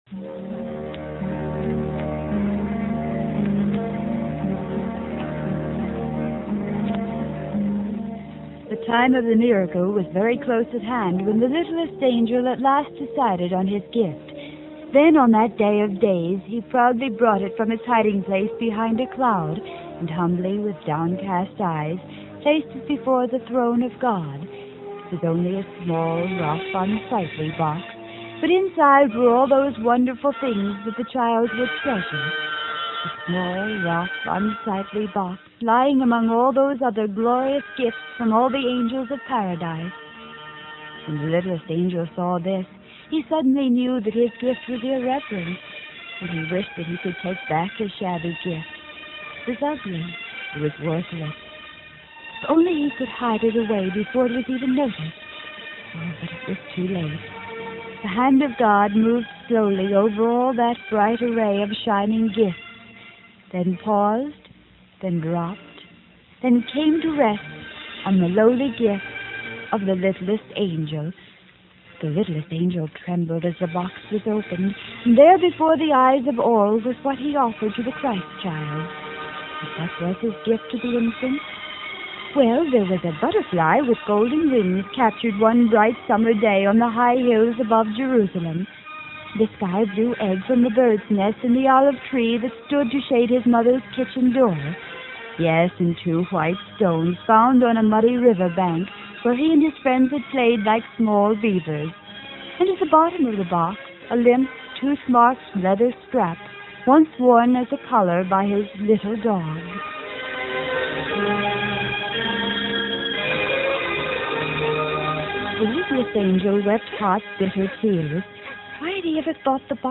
These 15-minute shows were taped about three weeks in advance in Studio A and were aired each weekday at 9:45am on WFIL.
The shows were basically live-to-tape.